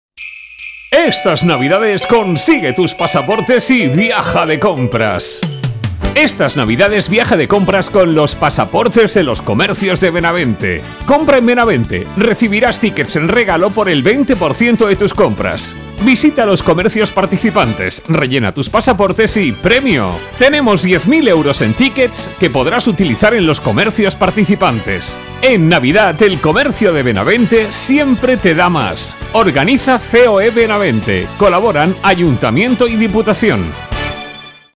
ESTA ES LA VOZ DEL LOCUTOR NACIONAL QUE GRABARA TU ANUNCIO
T-D PASAPORTE COMPRAS NAVIDEÑAS (CUÑA).mp3